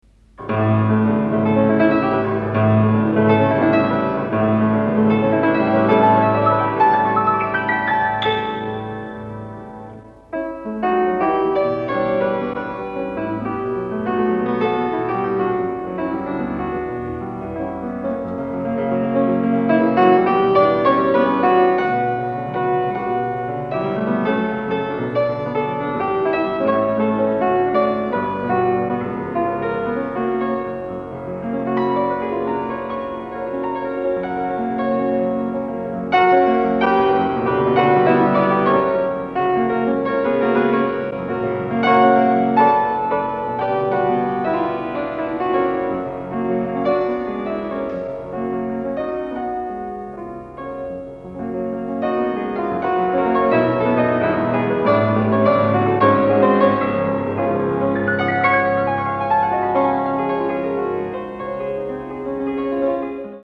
He often included it in his concerts, describing it in his programme notes as “vigorous and tempestuous, exploiting the full range of the keyboard”.
Return to Piano Solos